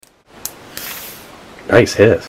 Play, download and share Nice hiss original sound button!!!!
nice-hiss.mp3